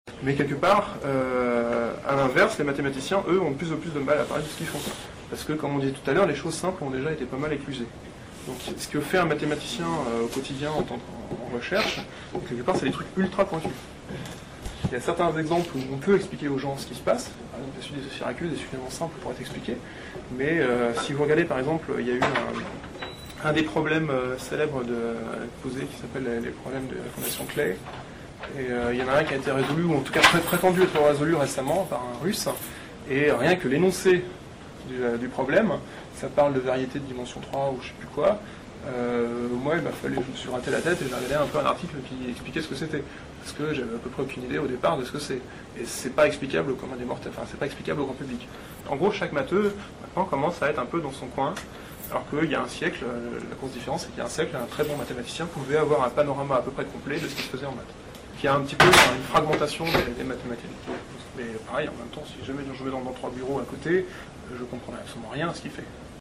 Interview interview sur ramification des mathématiques